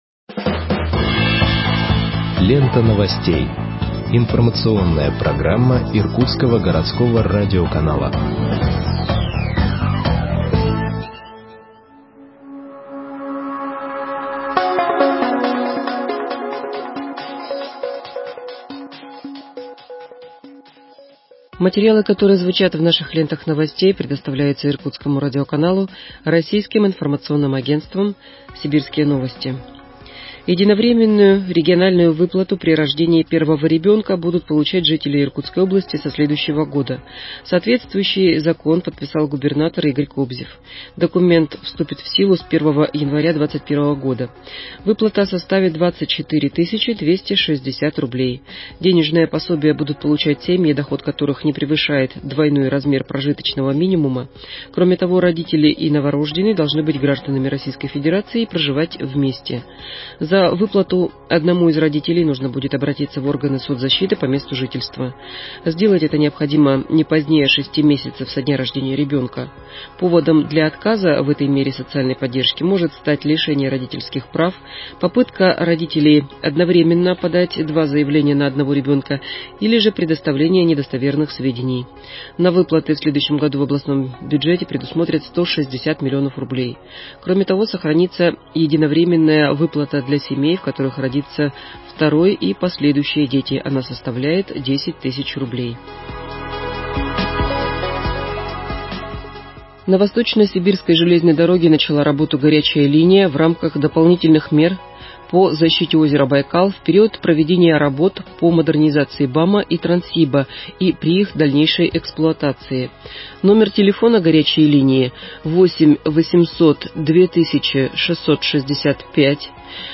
Выпуск новостей в подкастах газеты Иркутск от 26.11.2020 № 1